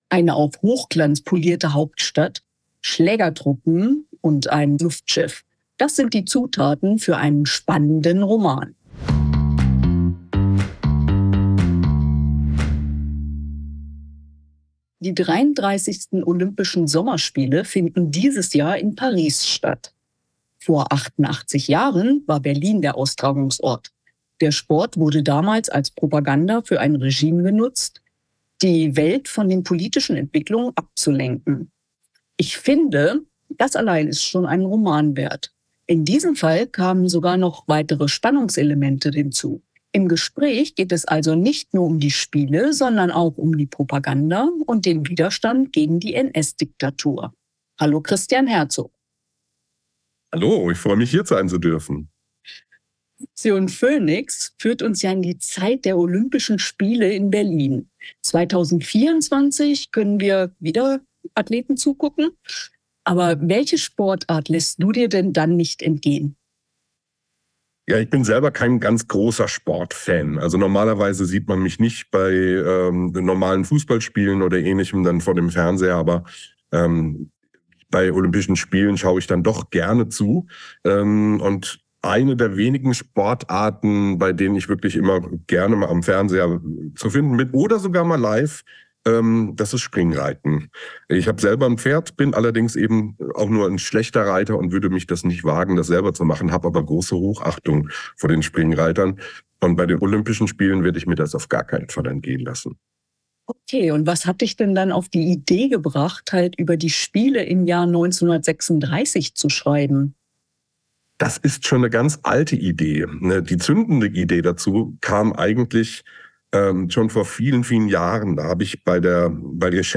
Im Interview verrät er die Entstehungsgeschichte des Romans.